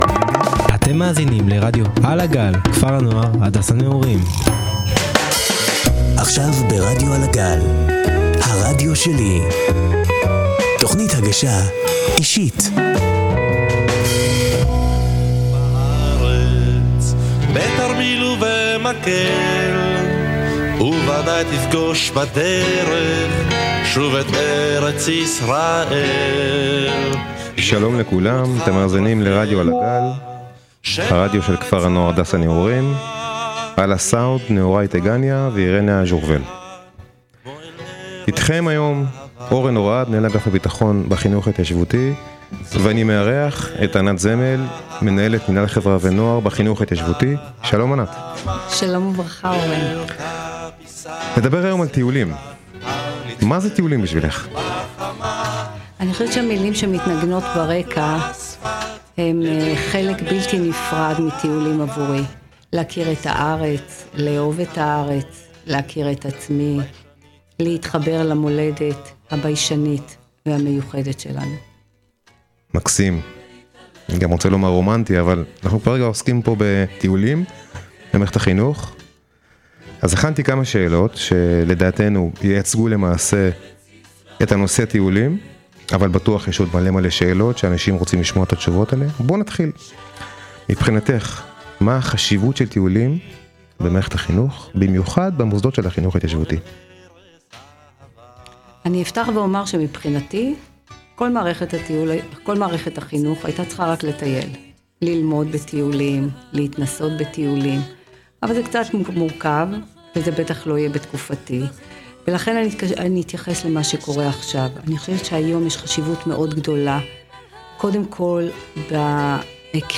בראיון